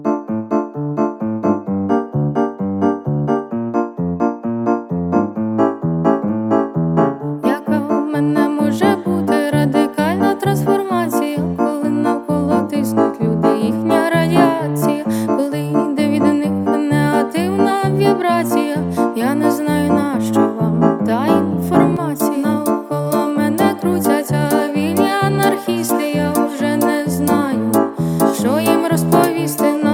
Жанр: Поп / Инди / Украинские
# Indie Pop